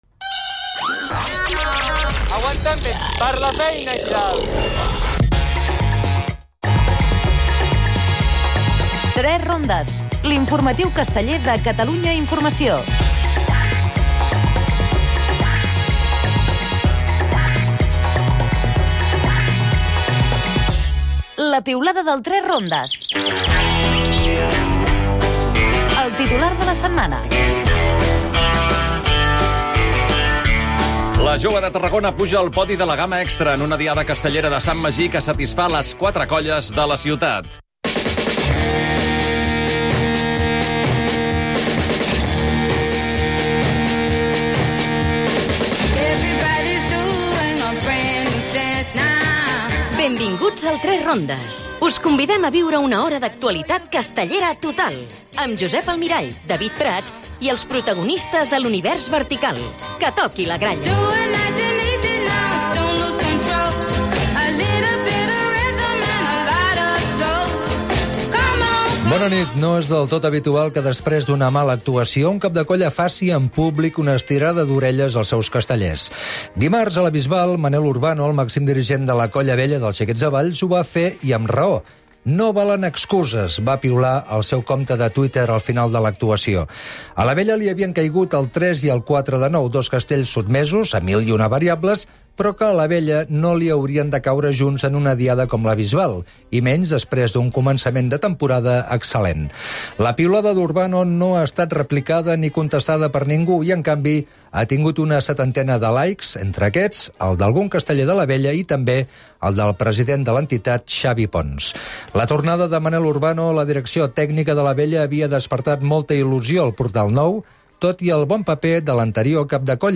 Al 3 Rondes hem estat a les diades de Mollet del Valls, Roda de Ber, Sant Mag, Asc, Grcia i la Bisbal del Peneds.